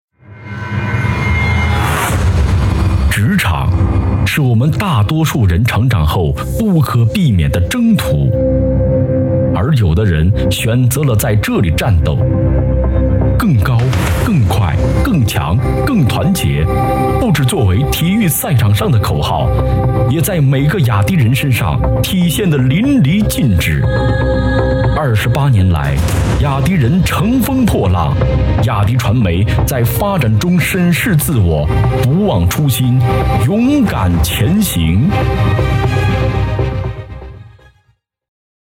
【男80号广告】雅迪
【男80号广告】雅迪.mp3